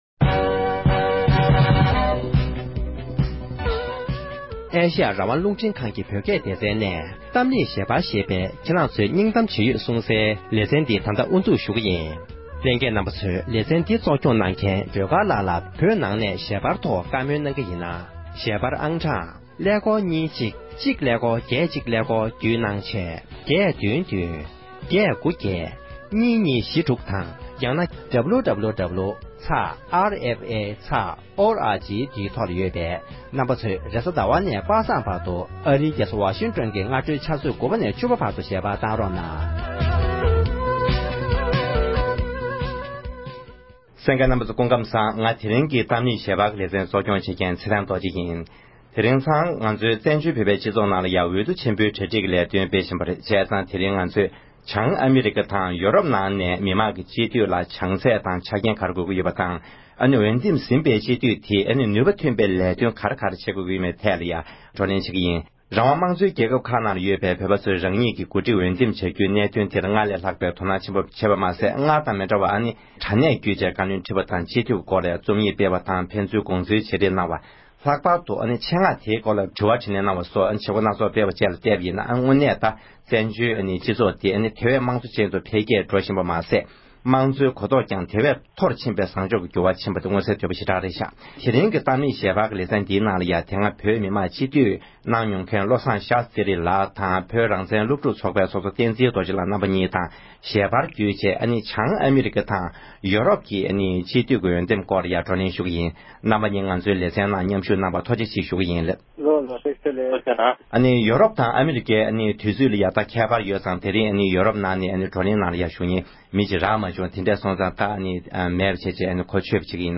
བྱང་ཨ་མི་རི་ཀ་དང༌ཡུ་རོབ་ཀྱི་བོད་མི་མང་སྤྱི་འཐུས་སྐབས་བཅོ་ལྔ་པའི་འོས་འདེམས་སྐོར་བགྲོ་གླེང༌།